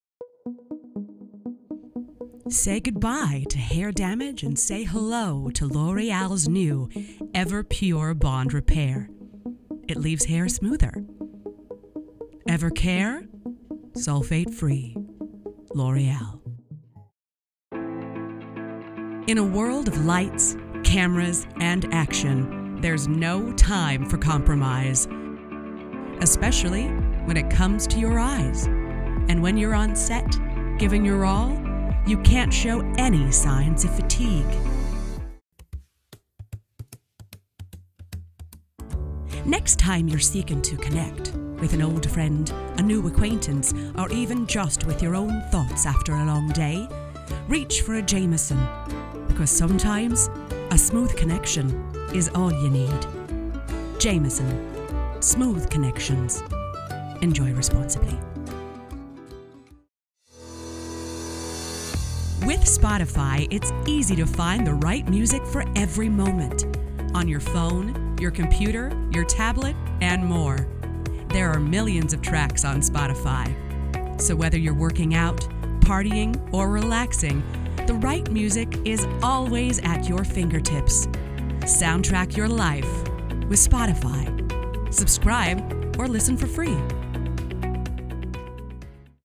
Gender: Female